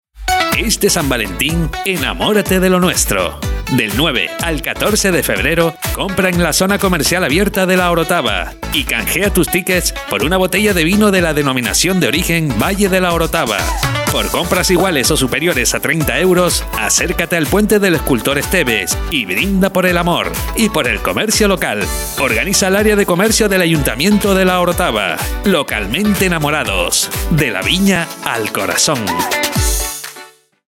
Cuña promocional